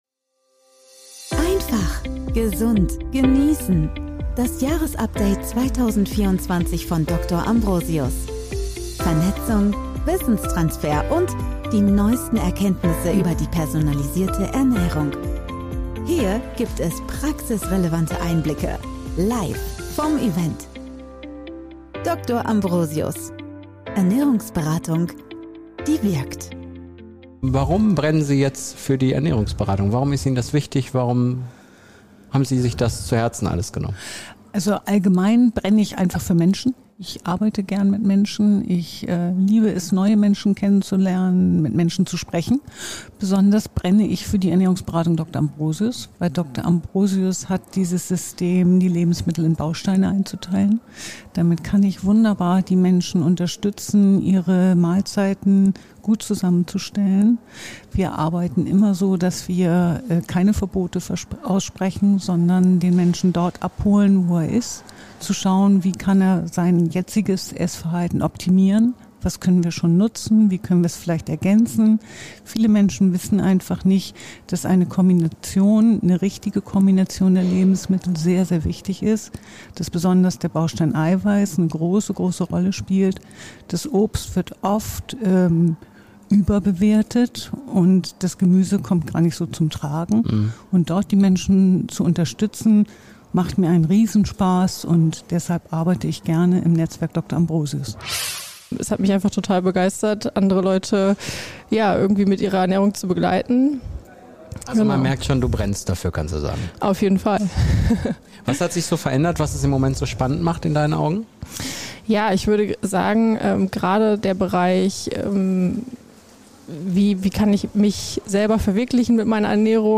Beschreibung vor 1 Jahr Beim diesjährigen Jahresupdate des DR. AMBROSIUS Netzwerkes haben sich viele Ernährungsberater*innen zusammen gefunden, um gemeinsam zurück, aber auch nach vorne zu blicken! In dieser Live-Aufzeichnung hören wir viele Stimmen von Teilnehmerinnen und erhalten einen Einblick in die persönliche Ernährungsberatung und wie das Event erlebt wurde.